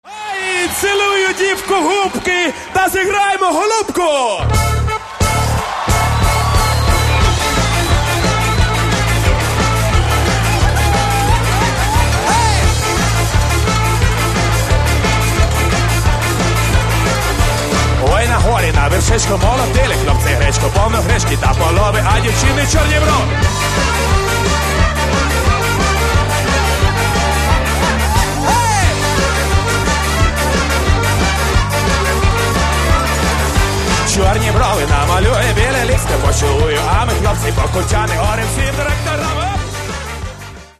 Каталог -> Народная -> Сборники